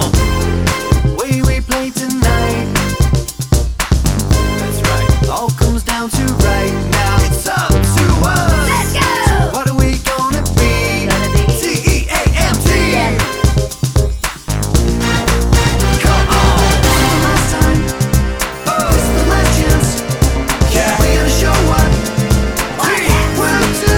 No Female Lead Part Soundtracks 4:27 Buy £1.50